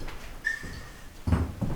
The activity included a moving "wire" in front of our DVR camera, when no one was in the room, and also we heard and collected a bunch of EVPs and two videos.
whistle
whistle.wav